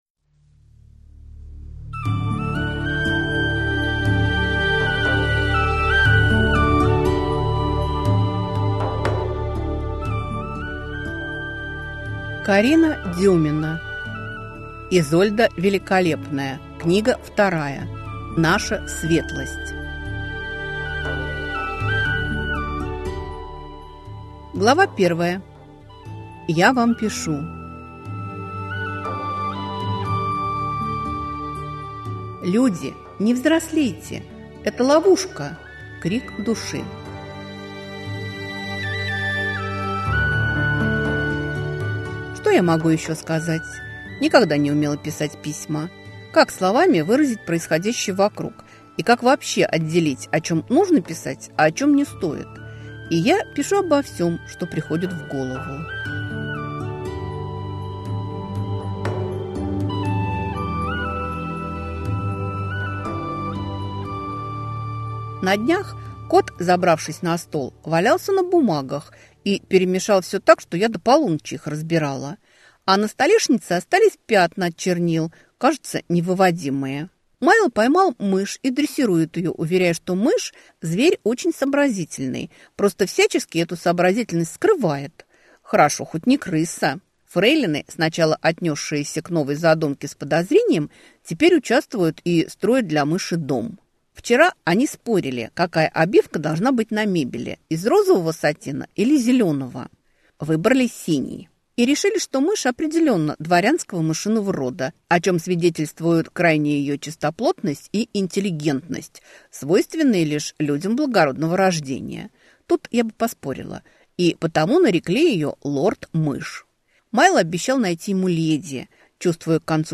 Аудиокнига Наша Светлость | Библиотека аудиокниг